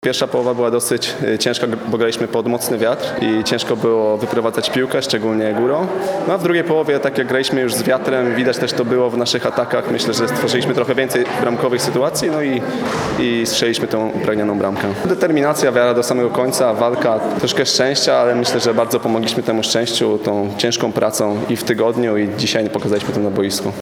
pomocnik suwalskich Wigier.